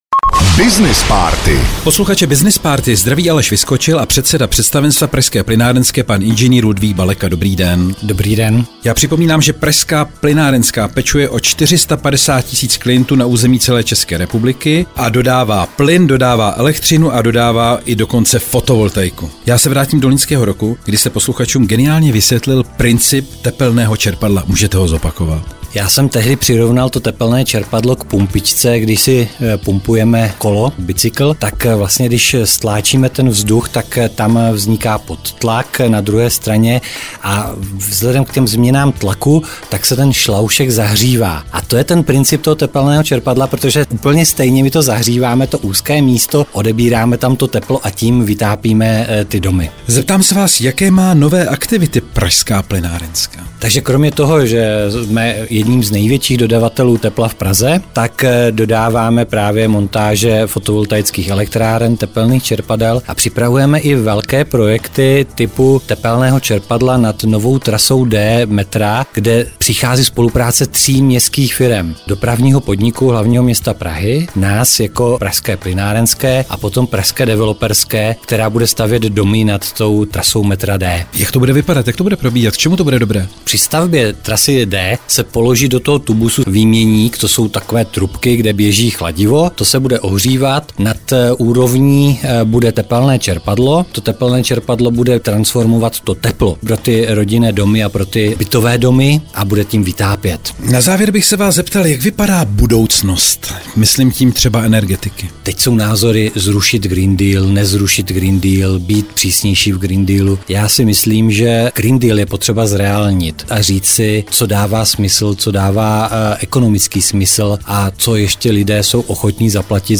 Veškerá témata rozhovorů na Frekvenci 1 jsou dostupná na uvedených odkazech:
Rozhovor 3
rozhovor_03.mp3